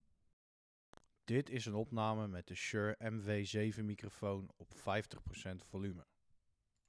De microfoon is helder, heeft een mooie klank en pakt vrij weinig omgevingsgeluid op. Dus ook voor een streamer of iemand die veel online calls heeft, is deze microfoon aan te raden.
Hieronder zijn nog twee microfoonopnames te vinden met de Shure MV7 om een indruk op te doen van de opnamekwaliteit. Deze opnames zijn digitaal gemaakt terwijl de microfoon via USB is aangesloten.
Opname Shure MV7 - 50% volume
Opname-Shure-MV7-50-Procent.mp3